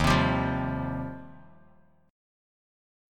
D#6add9 chord